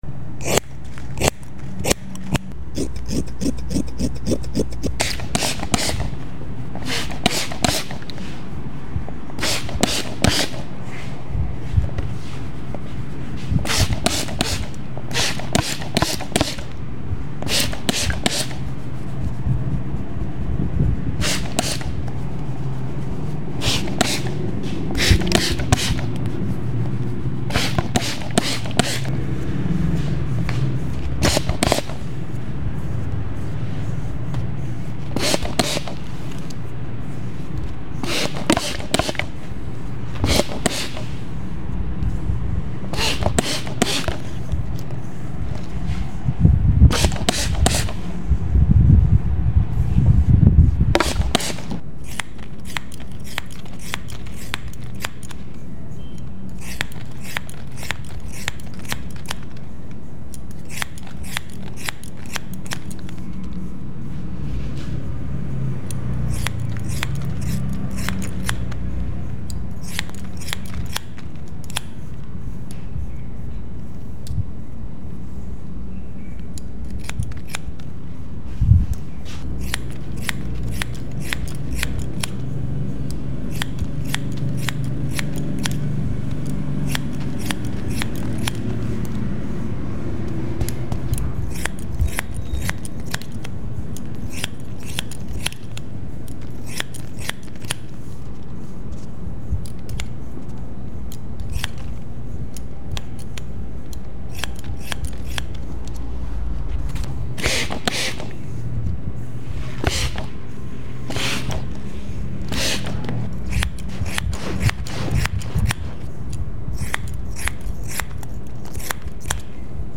Real ASMR Scissor Haircut ✂ sound effects free download
Real ASMR Scissor Haircut ✂ | Tokyo-Style Relaxing Sounds - No Talking